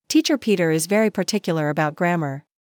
TONGUE TWISTER 早口言葉
/tí:tʃɚ pí:tɚ iz véri pɚtíkjəlɚ əbaut grǽmɚ/
Tongue-Twister-ɚ.mp3